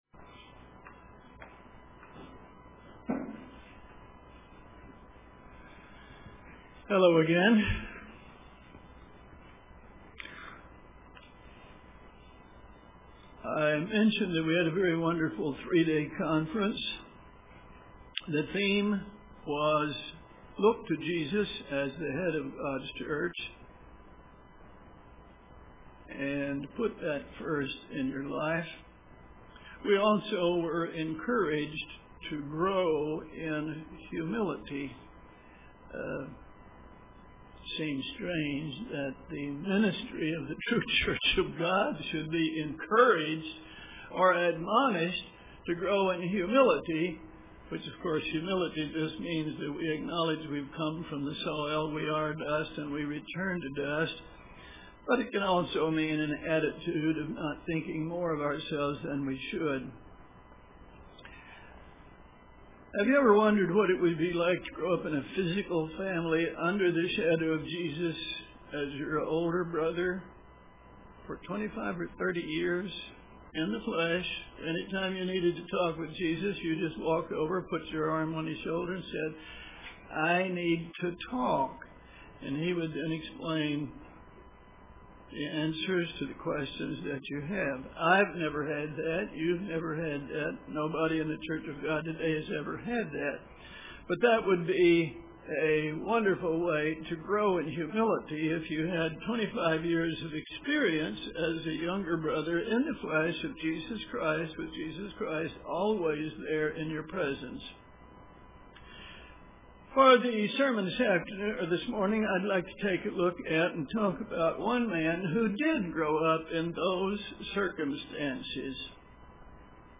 Biography of James UCG Sermon Studying the bible?